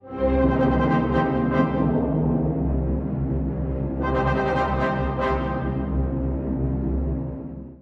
↑古い音源なので聴きづらいかもしれません！（以下同様）
冒頭の金管は「苦難」と言われています。
異常な閉塞感がありますね。
つづく木管と弦は、このモチーフの形を変え、民衆の悲嘆のような旋律を奏でます。